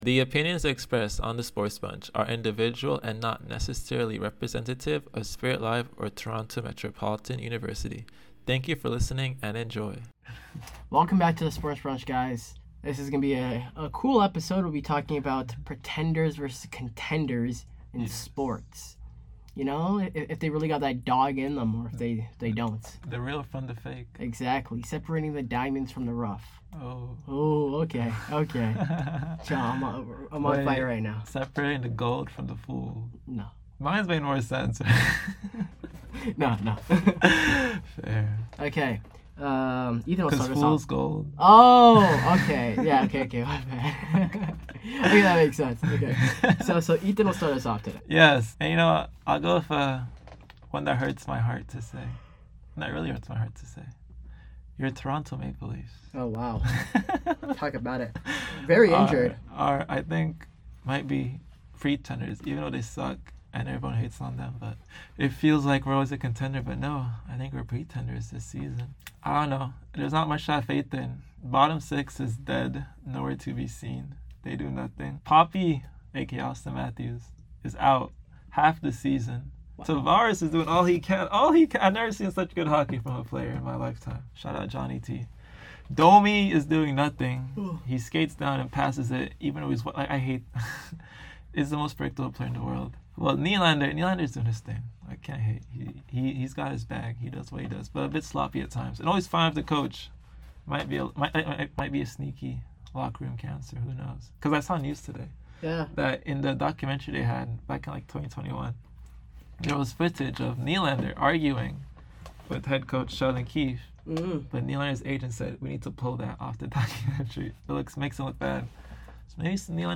Two friends. One mic.
The SportsBunch is where sports talk gets loud, unfiltered, and fun.
Expect fiery opinions, friendly chaos, and quizzes or games to wrap up each show.